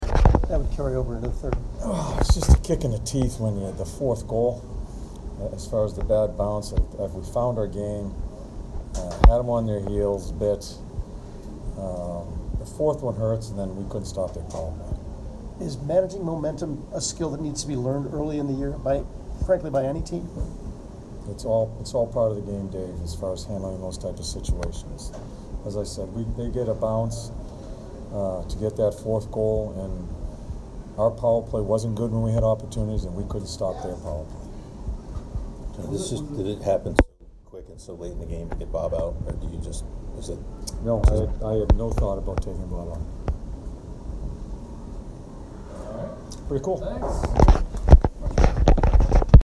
John Tortorella post-game 10/13